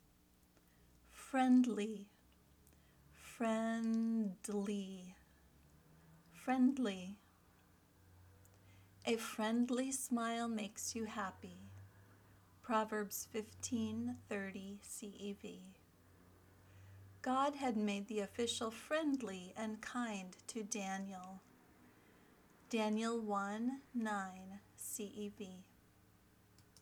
ˈfrɛn dli  (adjective)
vocabulary word – friendly